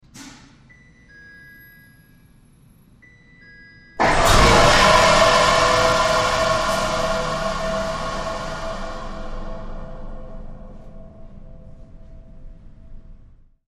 Shock Fire; Warning Beeps, Heavy Power Surge And Whoosh